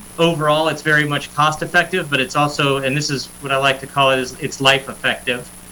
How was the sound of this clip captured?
On Tuesday, the Dubuque Area Chamber of Commerce and Greater Dubuque Development Corporation hosted a virtual town hall to provide updates about Dubuque’s air service.